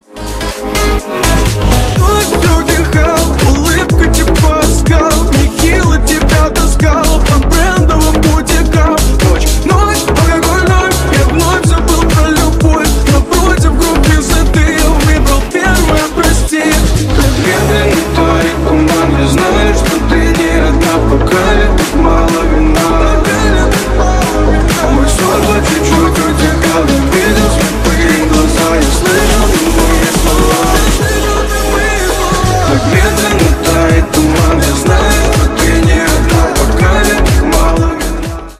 клубные
рэп